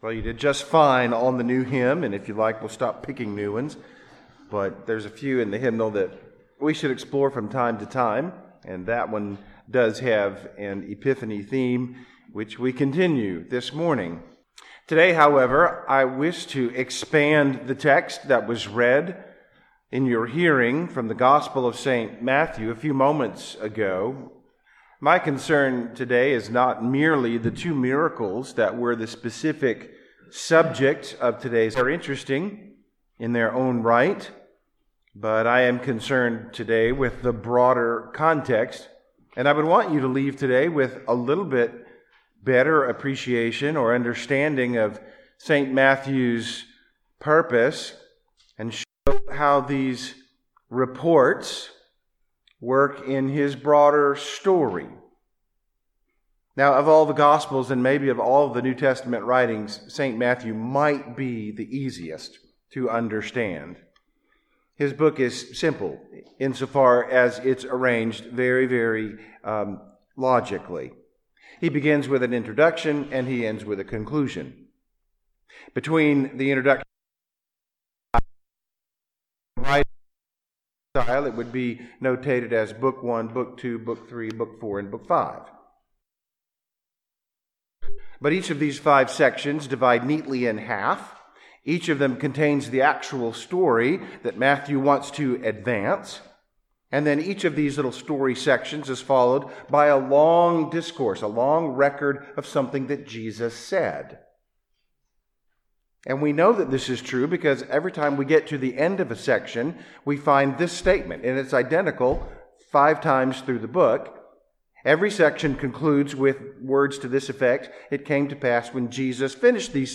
Sermons by All Saints Church, Lynchburg, Virginia (Anglican/Reformed Episcopal)